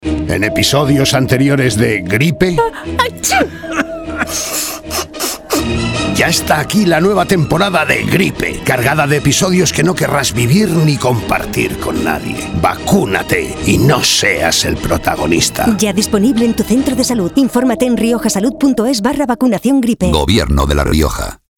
Cuña radiofónica